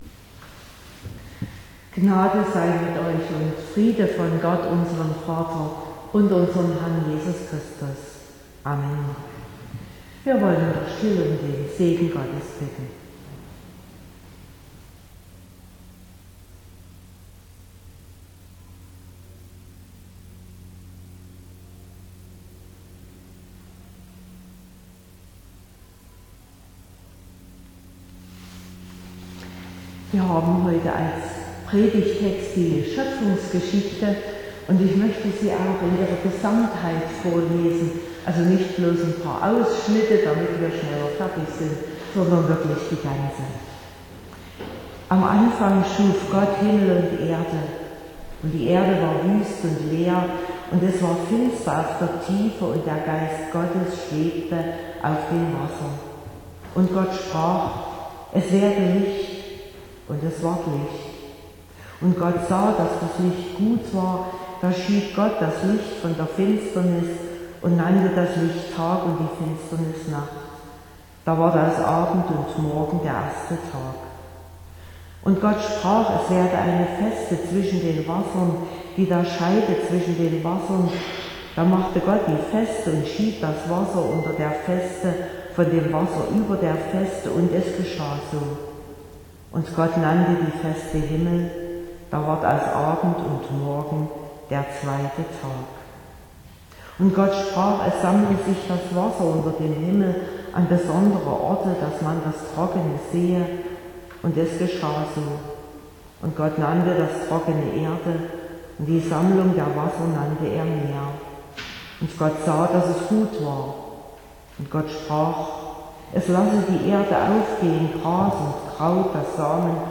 08.05.2022 – Gottesdienst
Predigt (Audio): 2022-05-08_Gesegnet_von_der_Schoepfung_an.mp3 (35,4 MB)